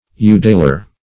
Udaler \U"dal*er\, Udalman \U"dal*man\, n.